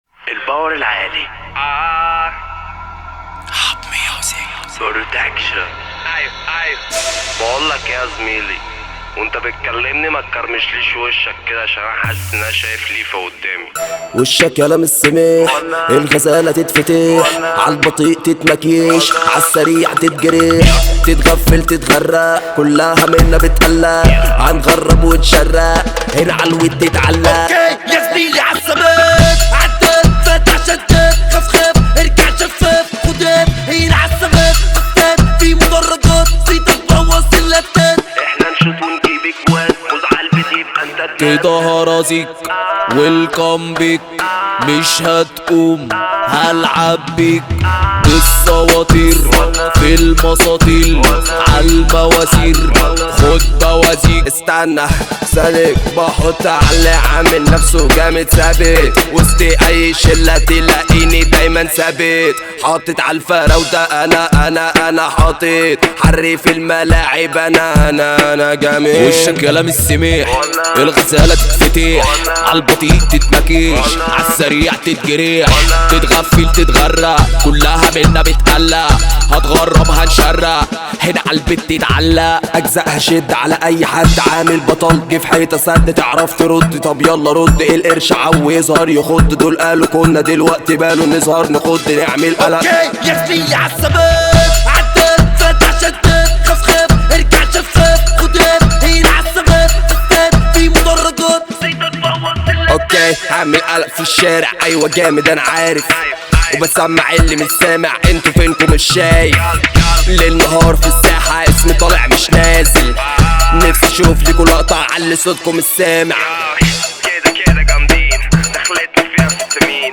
اغاني مصرية